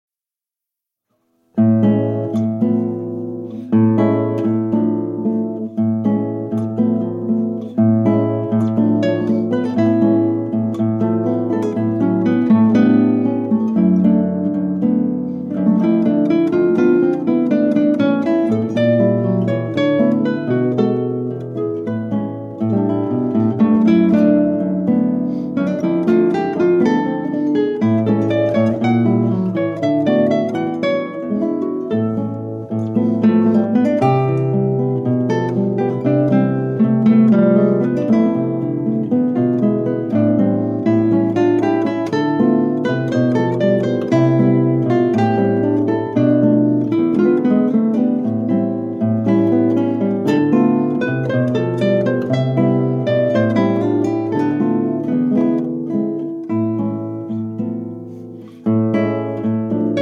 Duo works from South America
Guitar